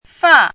chinese-voice - 汉字语音库
fa4.mp3